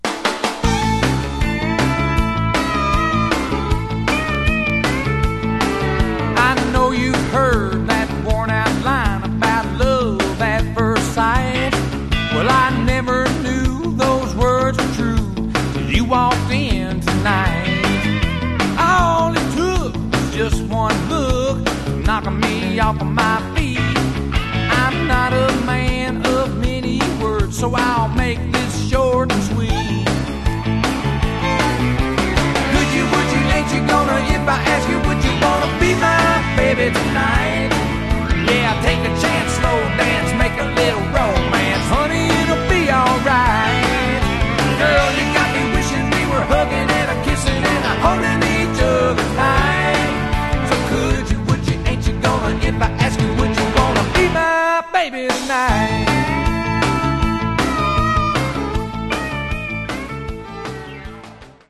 Genre: Country
This one borders on Rockabilly.